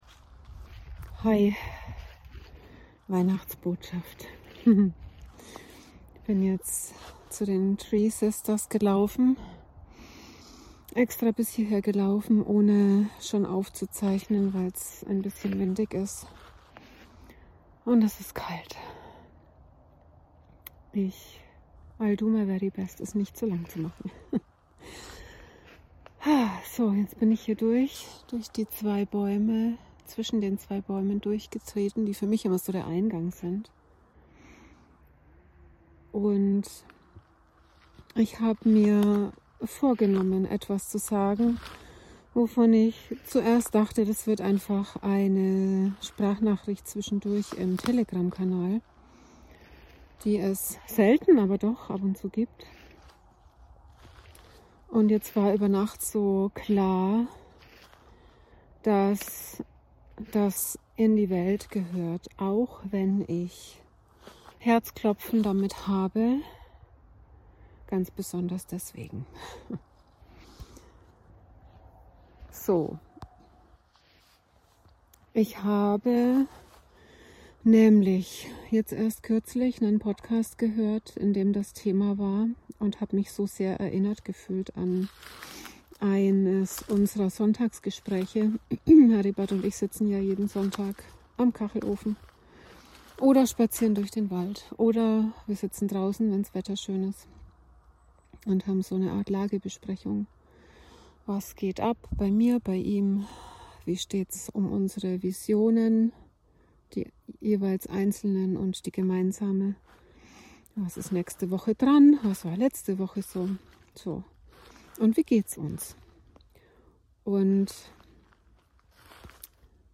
Meine WeihnachtsBotschaft 2025, gesprochen im KeltenWald inmitten der Tree Sisters.